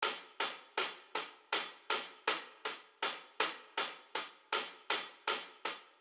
Hihat Loop.wav